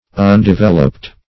undeveloped - definition of undeveloped - synonyms, pronunciation, spelling from Free Dictionary